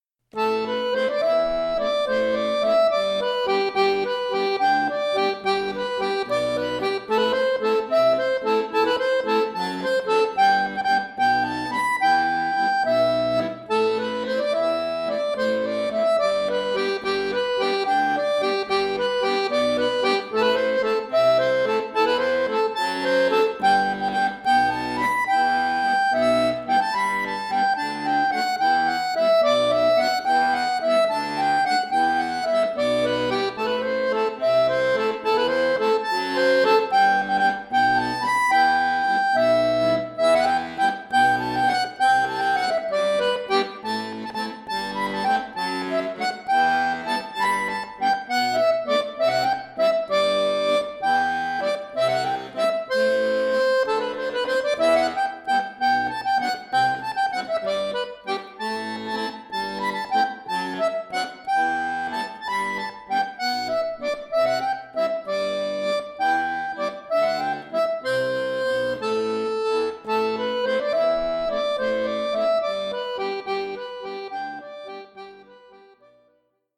Folksong , Irish